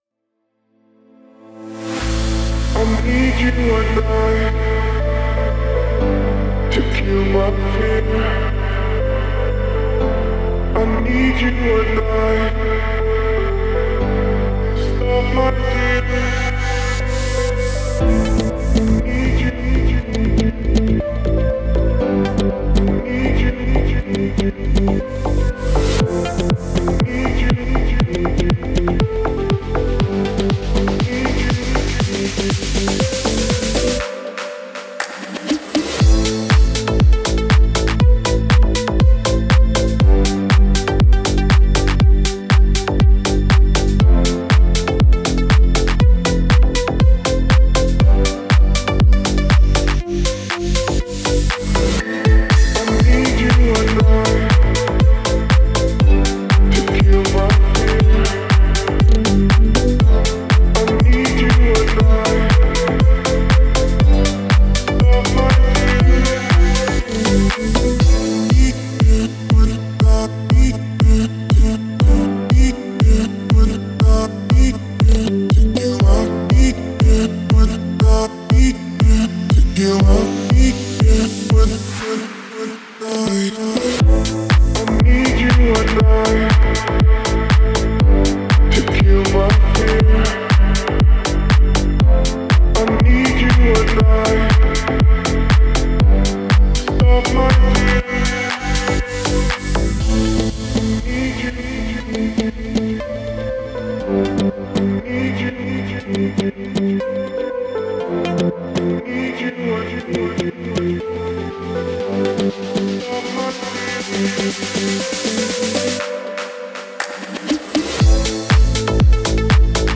это эмоциональная поп-песня